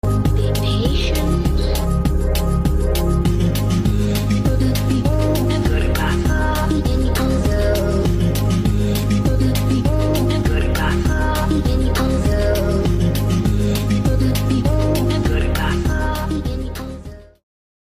Also wanted to make this seem like an old tape/tv effect so srry if it bothers anyone